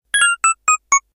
powerUp3.ogg